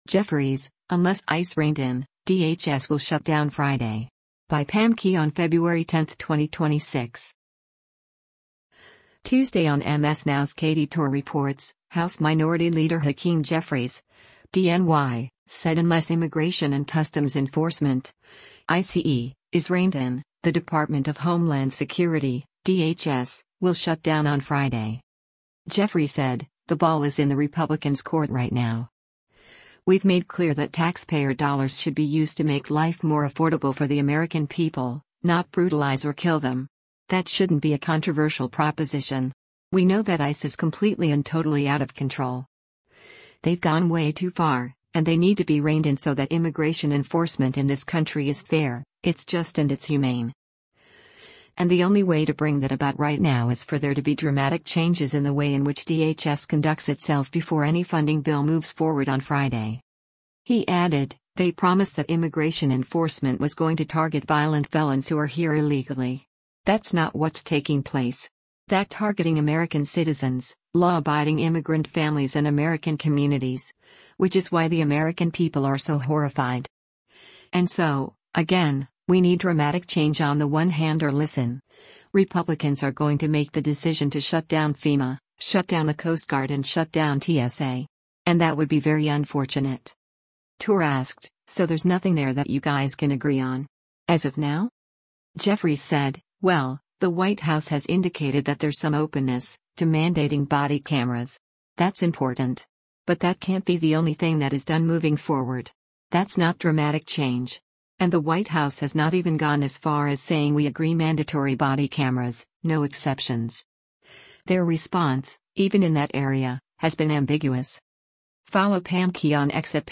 Tuesday on MS NOW’s “Katy Tur Reports,” House Minority Leader Hakeem Jeffries (D-NY) said unless Immigration and Customs Enforcement (ICE) is “reined in,” the Department of Homeland Security (DHS) will shut down on Friday.